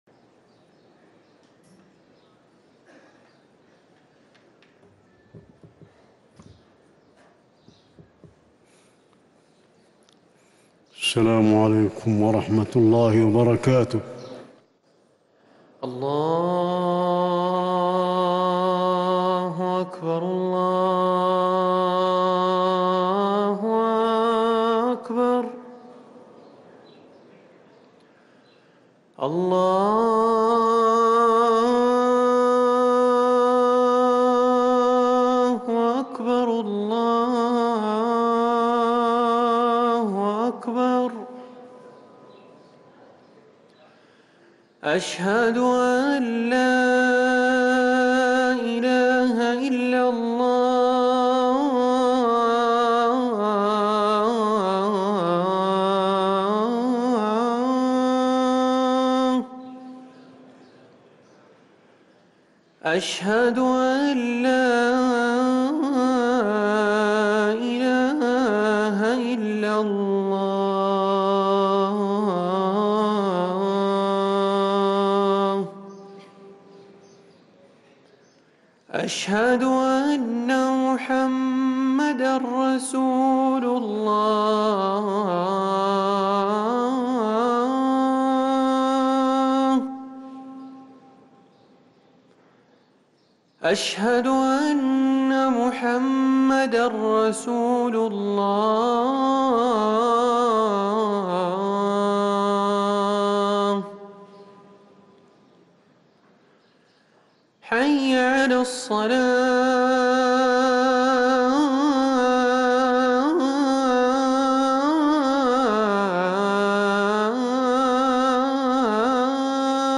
أذان الجمعة الثاني
ركن الأذان